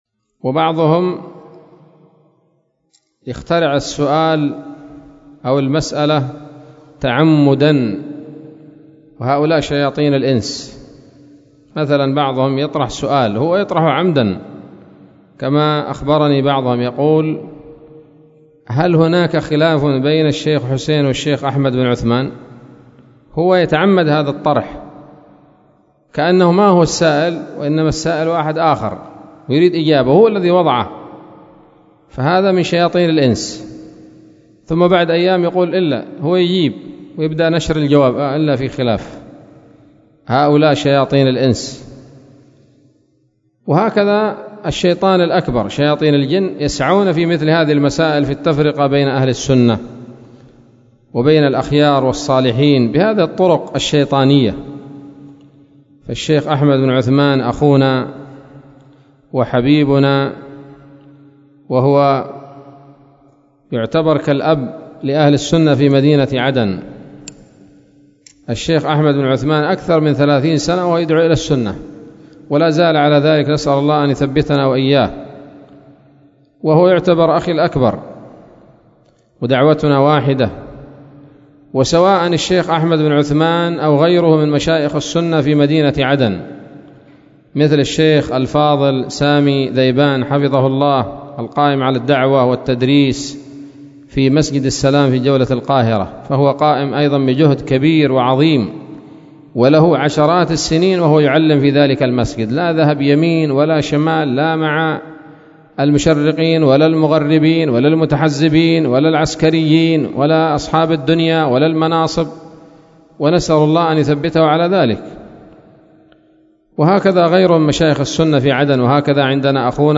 كلمة قيمة بعنوان: (( الثناء الحسن على أهل عدن النبلاء )) ليلة الثلاثاء 17 صفر 1444هـ، بدار الحيث السلفية بصلاح الدين